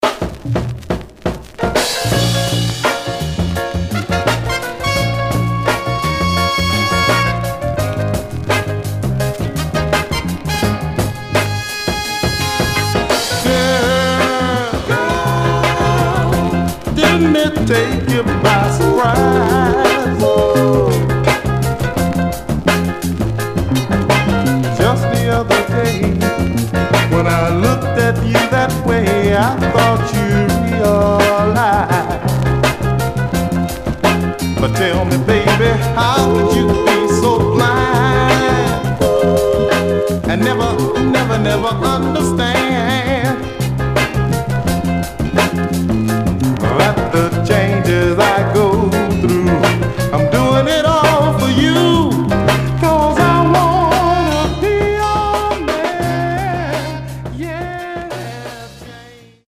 Some surface noise/wear
Mono